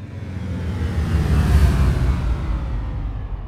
shipdescend.ogg